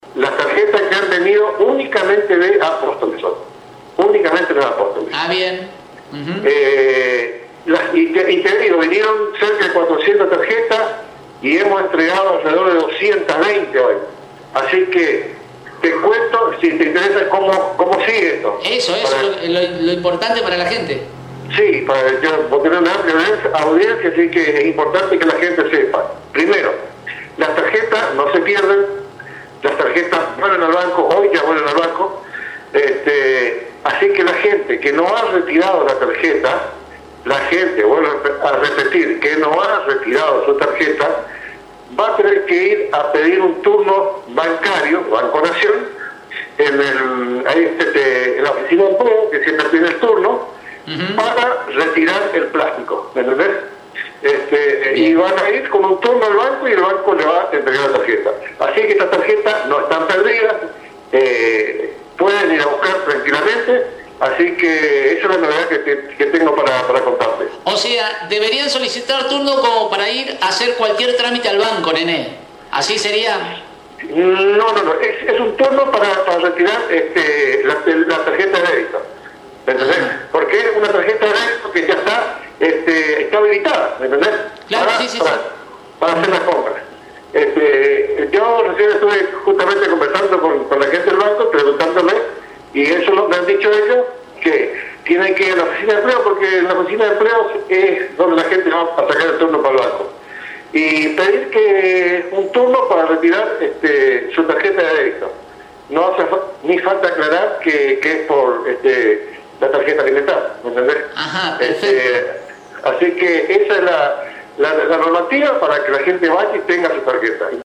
En charla con Radio Elemental el secretario de Acción Social de la Municipalidad de Apóstoles afirmó que recibieron unas 400 tarjetas, de las cuales entregaron 220; pero también clarificó como deben proceder los beneficiarios que aún no la tienen en mano, llevándoles tranquilidad con la afirmación que la mencionada ya queda en esta localidad, en el Banco Nación Argentina y, para poder obtenerla deben solicitar un turno para retirar tarjeta de Débito (ni siquiera deben aclarar que es para la Tarjeta Alimentar) en la Oficina De Empleo de esta localidad y, con ese turno acudir al banco para comenzar a utilizar el beneficio.